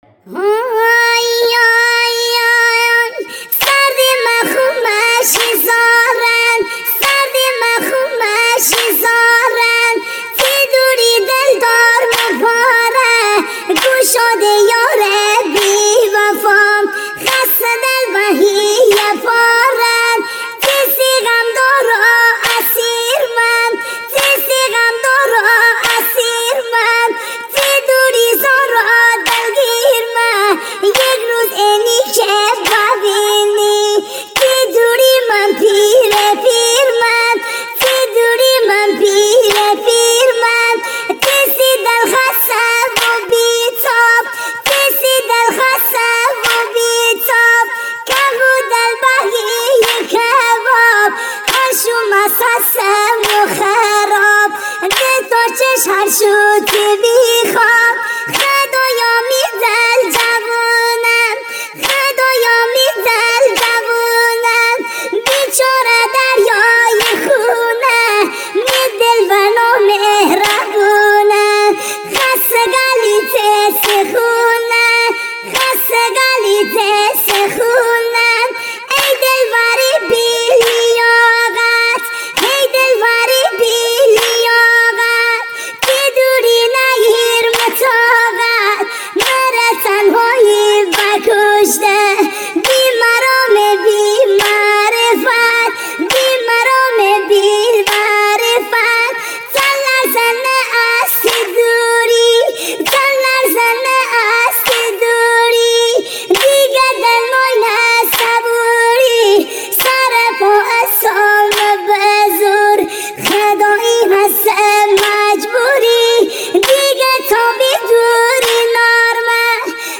۲۵ اهنگ با صدای بچه ریمیکس مازندرانی